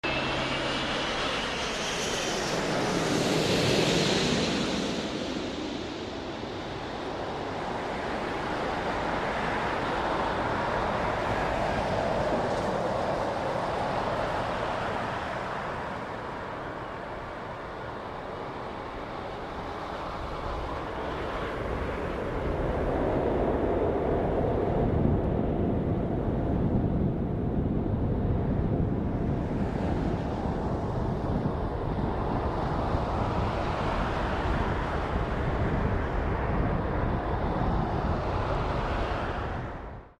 Spoilers Deployed, Reverse Thrust Selelcted